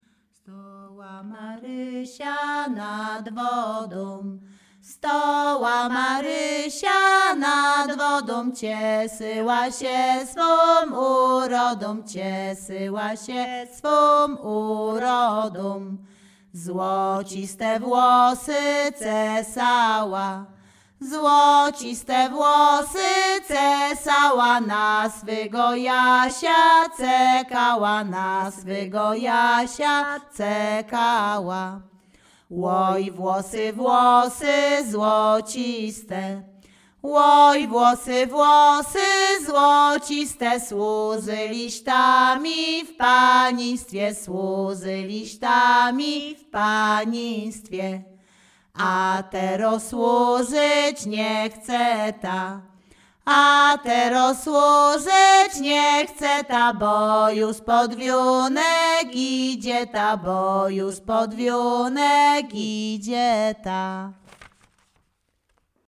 Śpiewaczki z Chojnego
województwo łódzkie, powiat sieradzki, gmina Sieradz, wieś Chojne
Weselna
liryczne miłosne weselne wesele